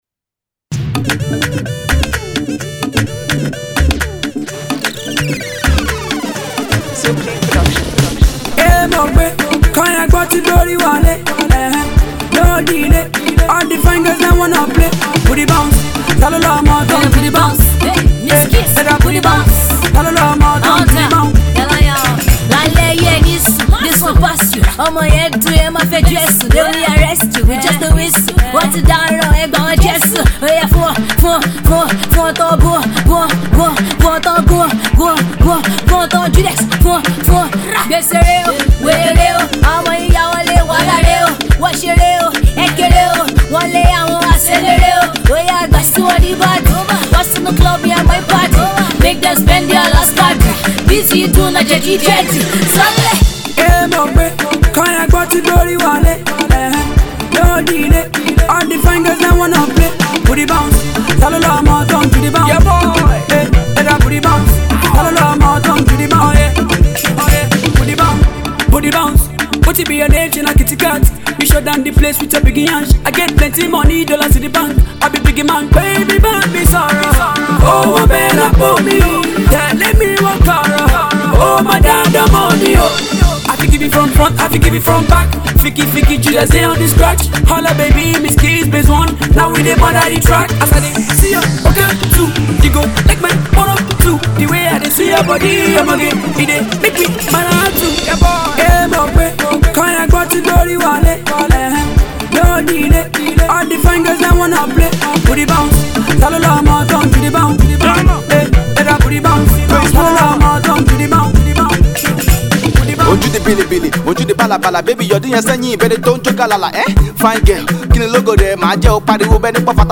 Home Afro-pop Audio